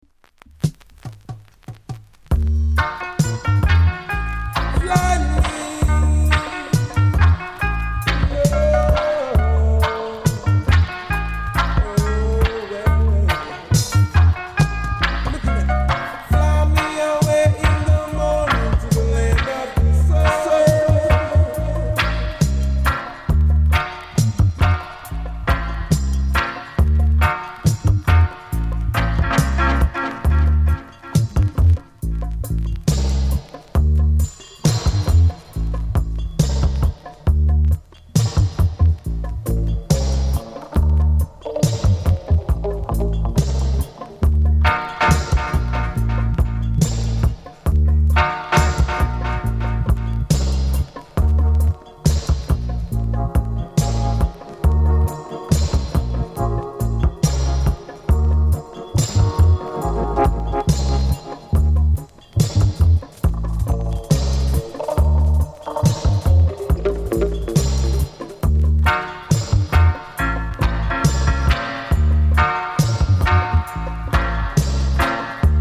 ※小さなチリノイズが少しあります。
コメント HEAVY UK ROOTS!!RARE!!